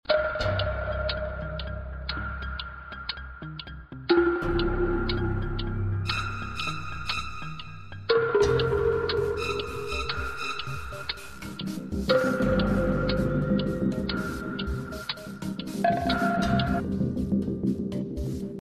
Thrilling And Intense Sound Effect 01 - Bouton d'effet sonore